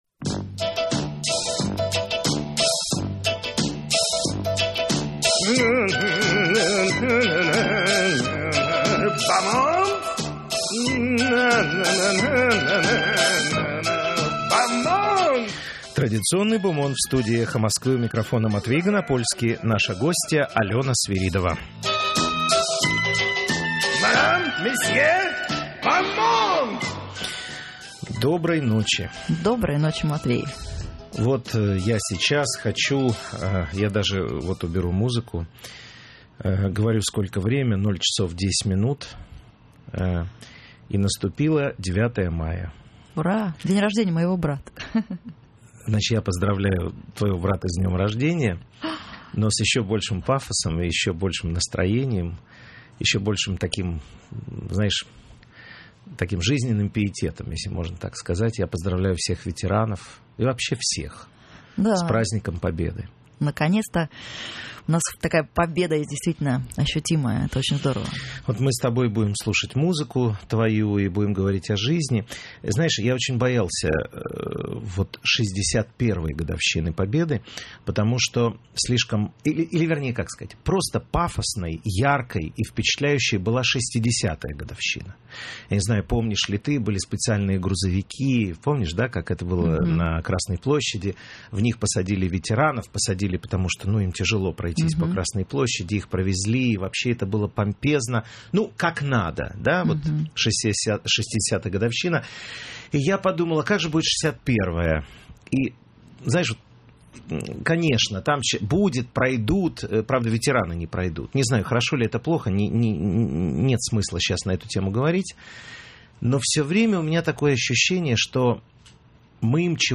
В эфире радиостанции «Эхо Москвы» - Алена Свиридова, певица.